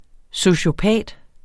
Udtale [ soɕoˈpæˀd ]